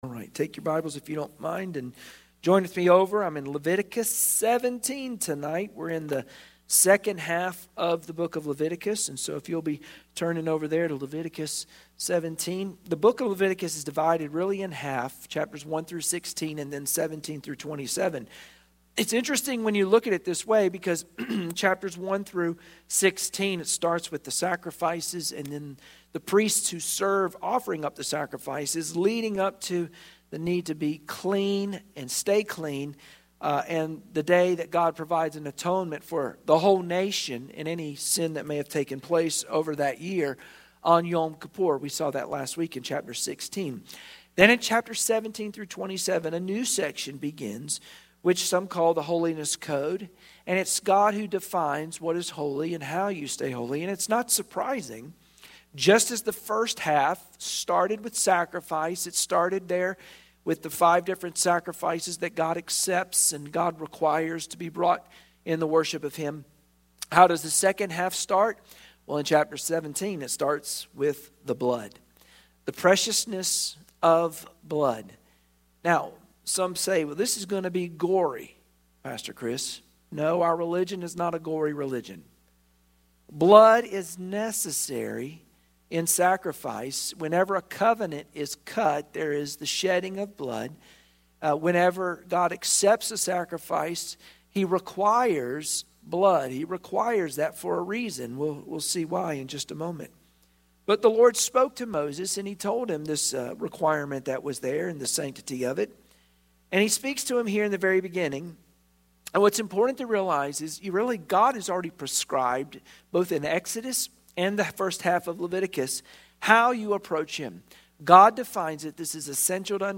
Wednesday Prayer Mtg Passage: Leviticus 17 Service Type: Wednesday Prayer Meeting Share this